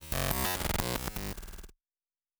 pgs/Assets/Audio/Sci-Fi Sounds/Electric/Glitch 1_06.wav at master
Glitch 1_06.wav